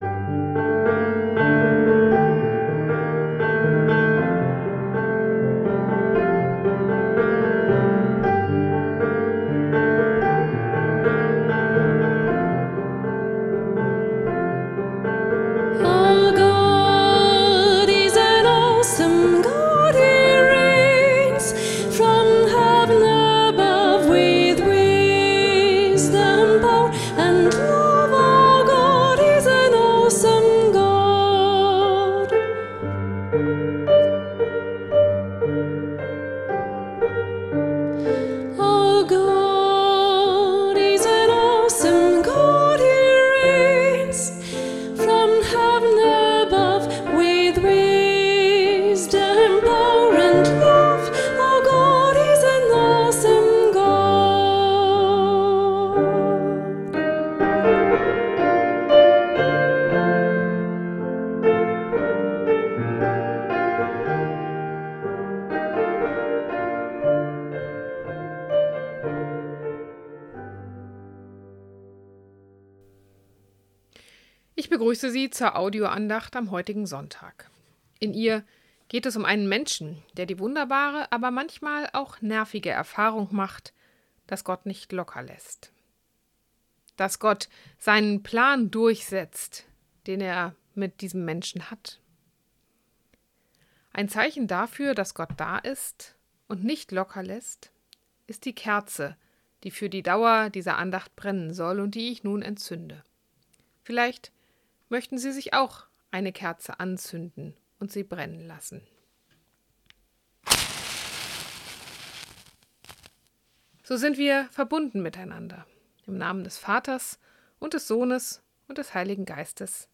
Andacht-Jona.mp3